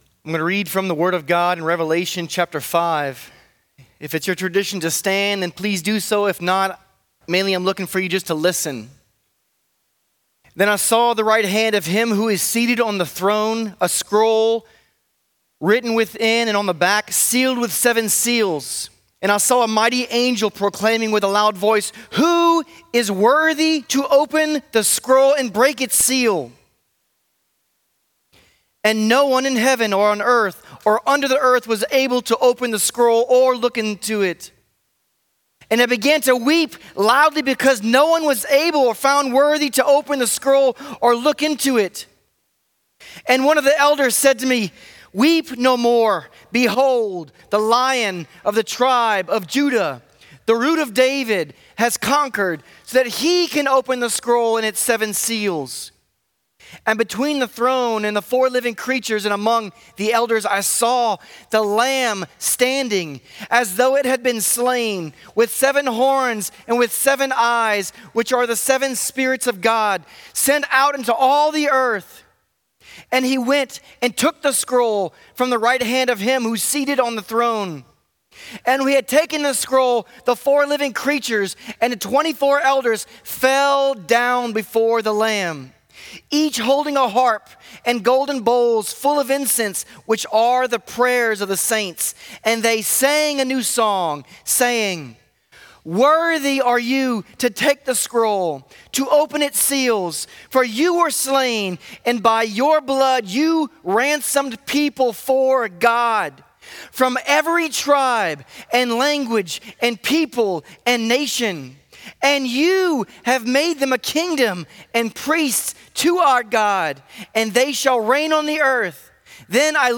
How Will They Call On Him? | Lafayette - Sermon (Revelation 5)